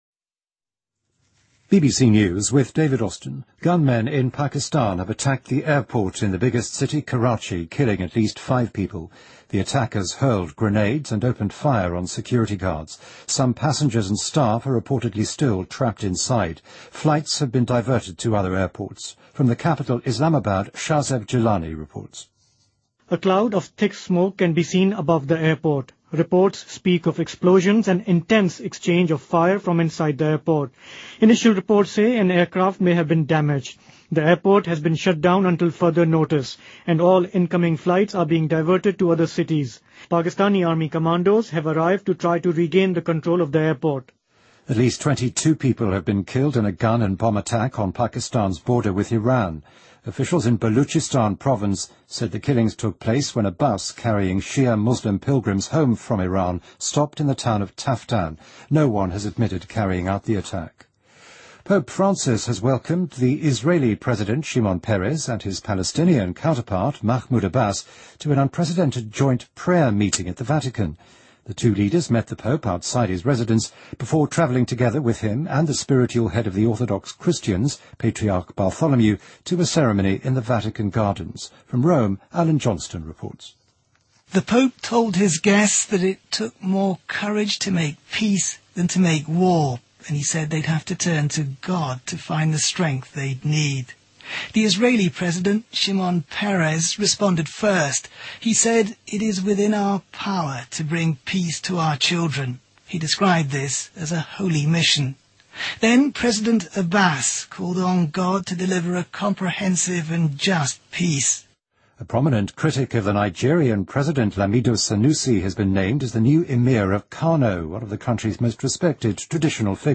Date:2014-06-09Source:BBC Editor:BBC News